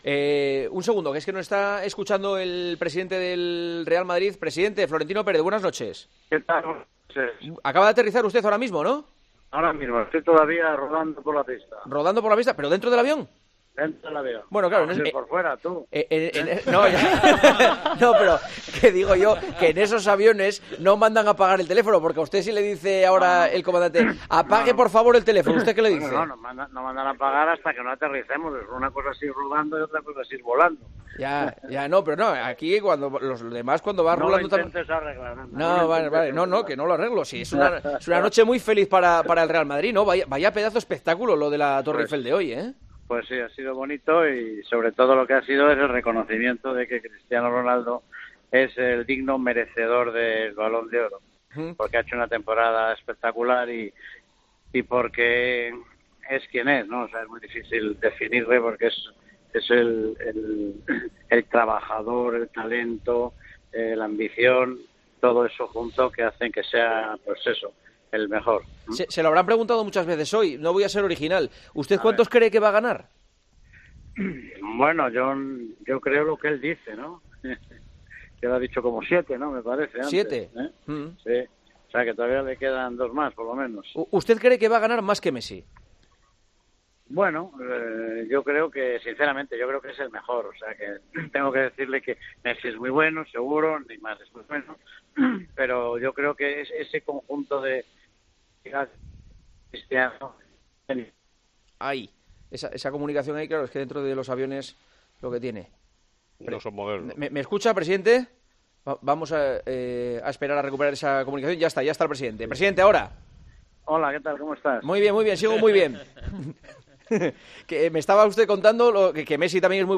Juanma Castaño charló este jueves con el presidente del Real Madrid a su llegada a la capita de España, procedente de la entrega del quinto Balón de Oro a Cristiano Ronaldo: "Cristiano es el digno merecedor del Balón de Oro por su temporada espectacular y por ser quien es.